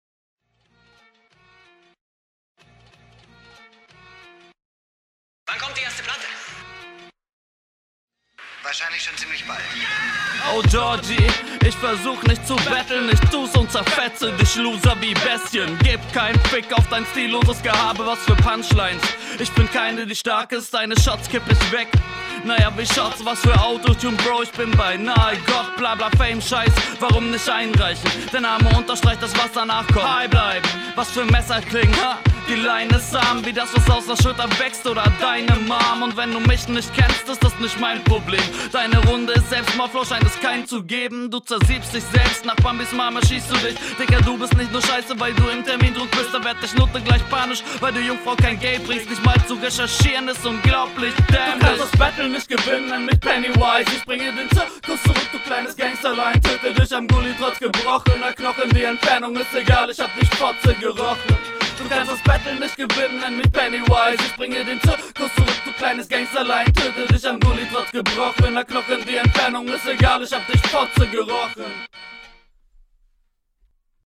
Man versteht dich besser als dein Gegner.
Du wirkst hier direkt etwas routinierter im Flow und der Betonung.